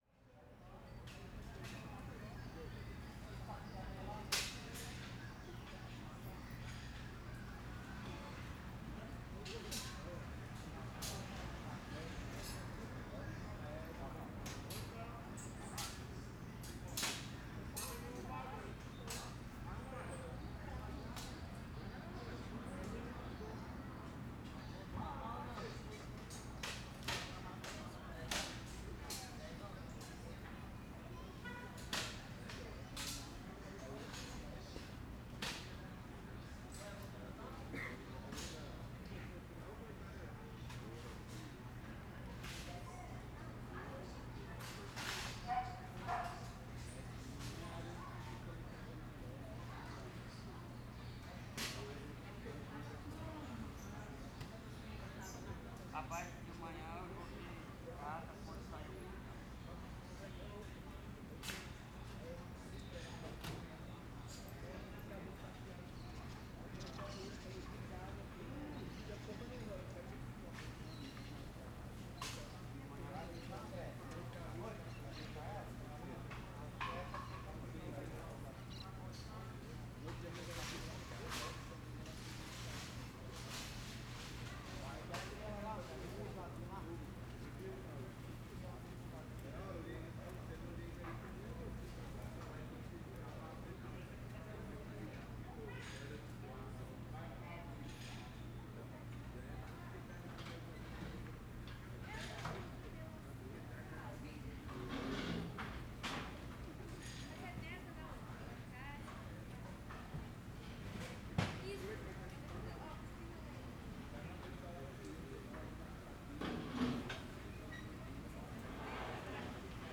Arquivo de pneu asfalto - Coleção Sonora do Cerrado
CSC-04-141-OL- Ambiente ruas na Vila Planalto vozerio homens passarinhos pneu carro no asfalto marteladas no metal.wav